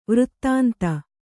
♪ vřttānta